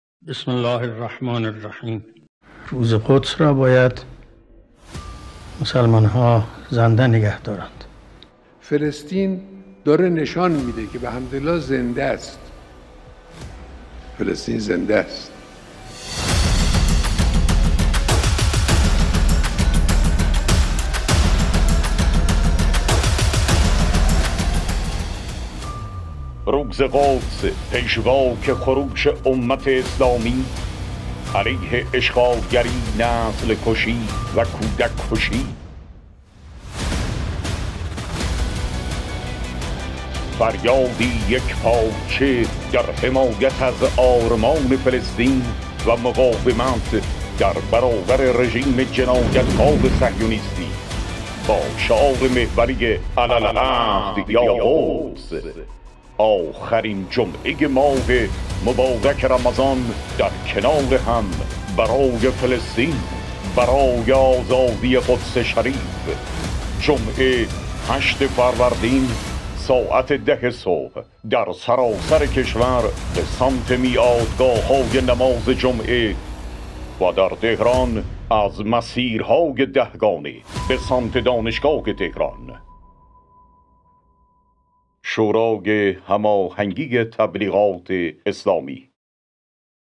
تیزر اطلاع رسانی مراسم روز جهانی قدس